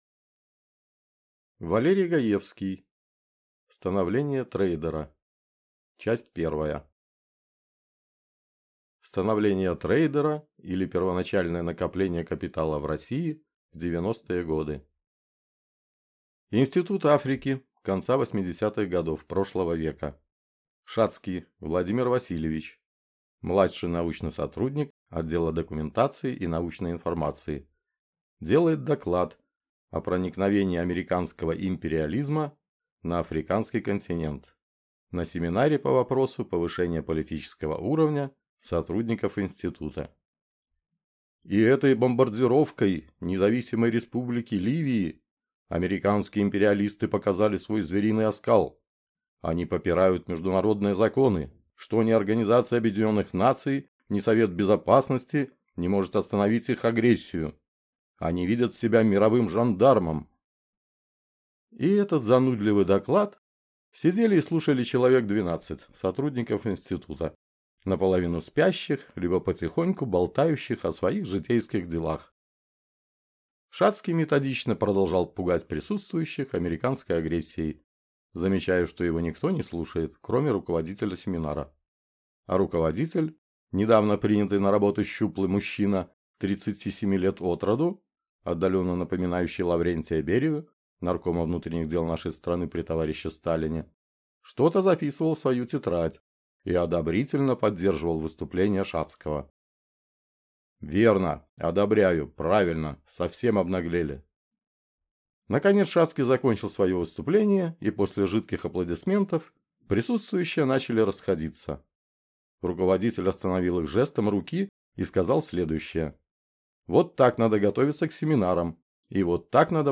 Аудиокнига Становление трейдера. Часть 1 | Библиотека аудиокниг